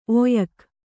♪ oyak